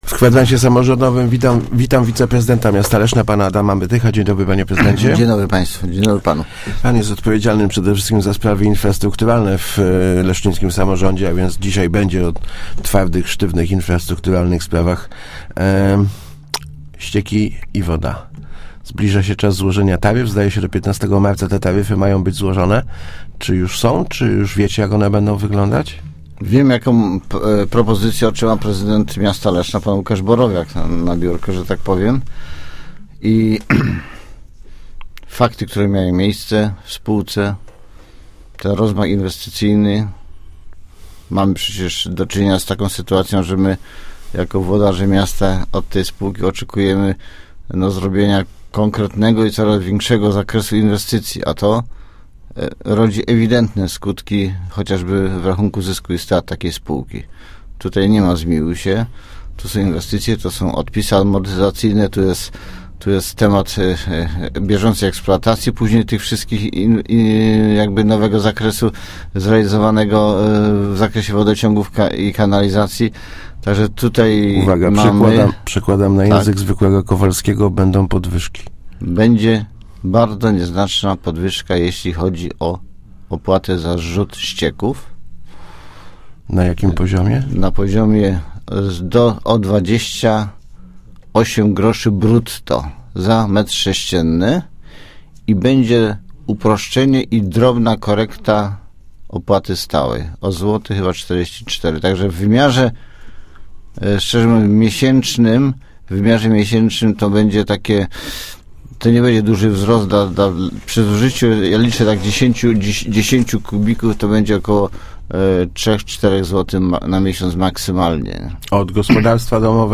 Miejskie Przedsi�biorstwo Wodoci�gów i Kanalizacji w Lesznie przygotowa�o nowe taryfy op�at za wod� i odbiór �cieków. -Planowana jest nieznaczna podwy�ka cen �cieków i op�aty sta�ej– powiedzia� w Kwadransie Samorz�dowym wiceprezydent Adam Mytych.